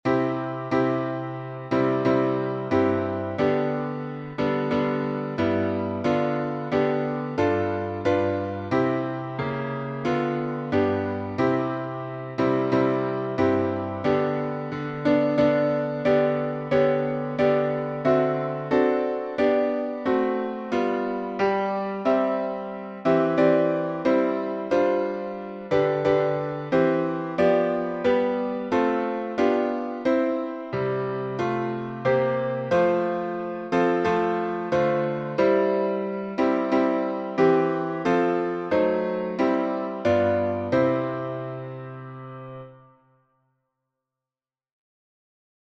Words by Joseph Bromehead (1747-1826)MATERNA by Samuel A. Ward (1847-1903)Key signature: C major (no sharps or flats)Time signature: 4/4Meter: 8.6.8.6.D. (C.M.D.)Public Domain1.